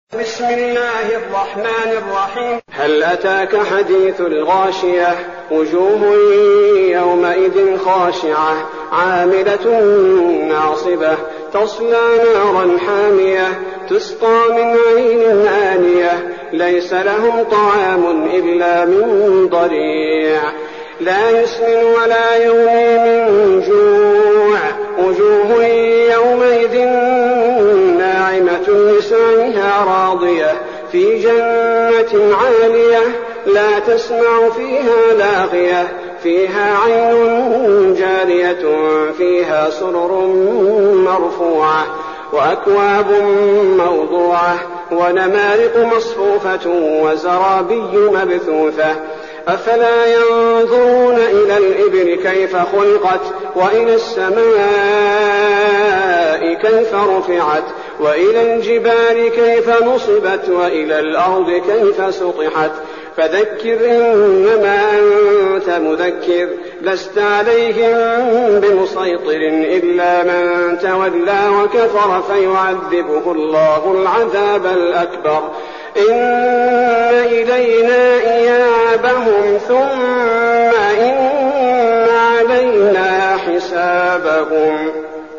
المكان: المسجد النبوي الشيخ: فضيلة الشيخ عبدالباري الثبيتي فضيلة الشيخ عبدالباري الثبيتي الغاشية